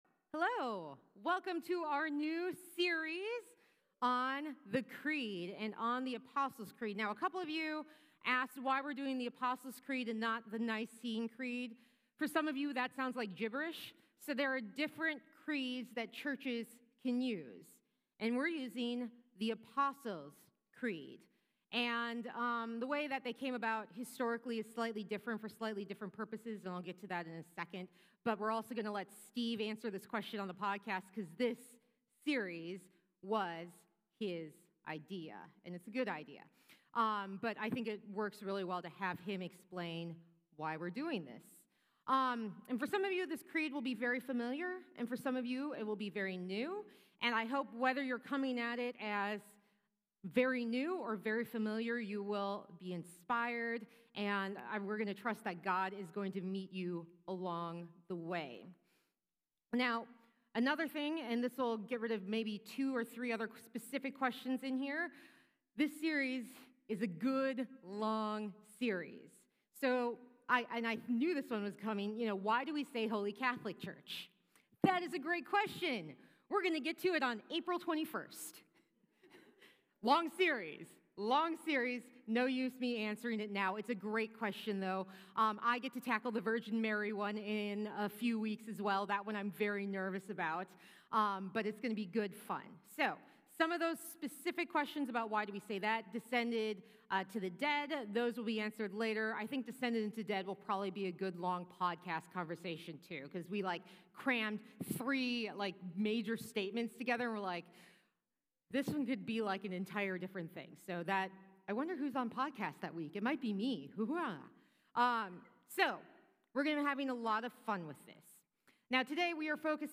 This week we start a new sermon series, exploring our faith through The Apostle's Creed.